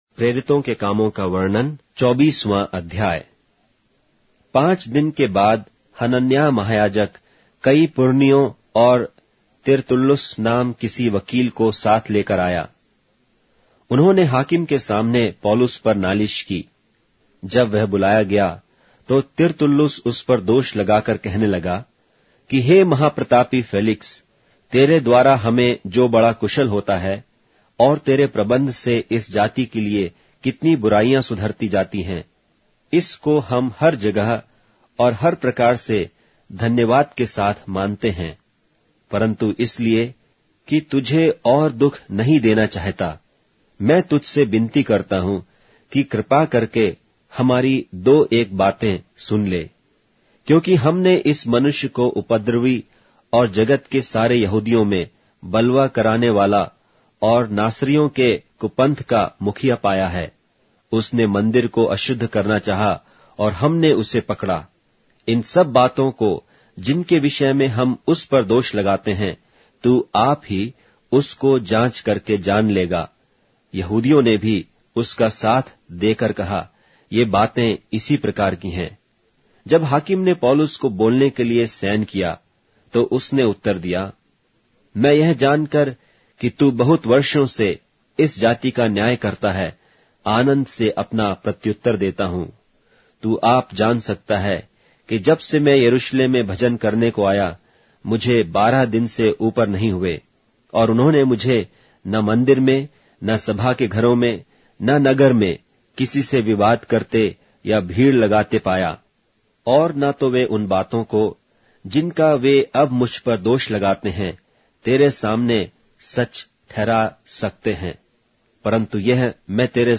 Hindi Audio Bible - Acts 1 in Nlv bible version